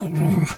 dog_2_growl_06.wav